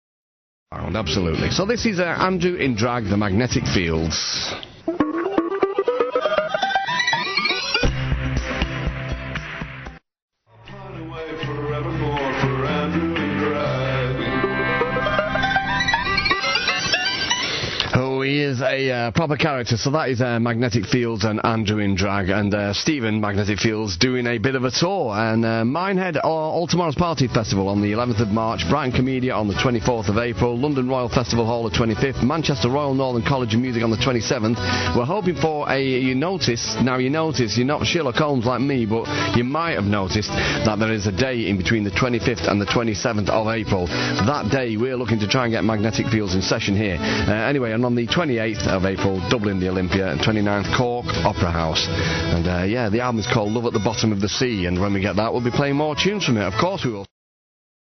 Music cue example